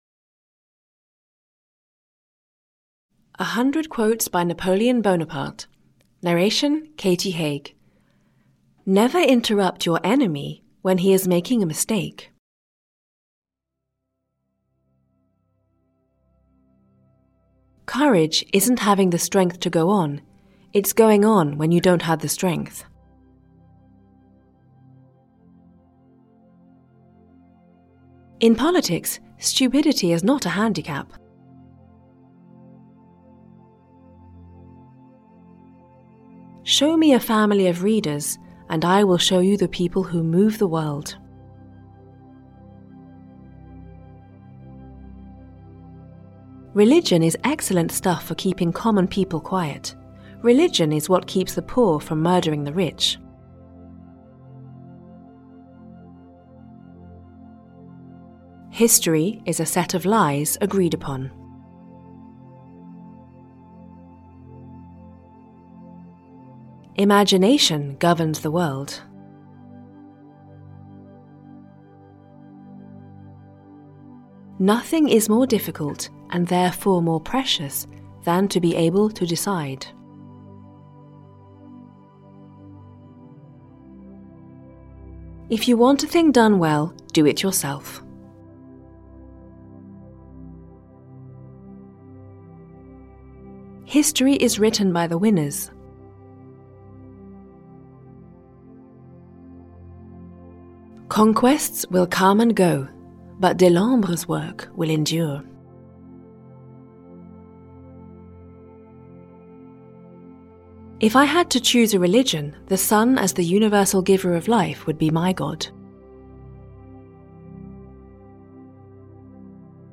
Ukázka z knihy
100-quotes-by-napoleon-bonaparte-en-audiokniha